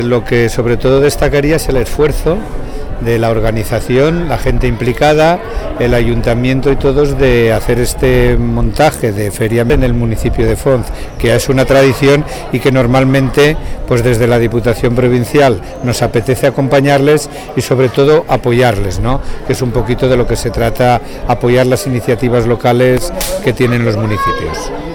Miguel Gracia, presidente de la DPH: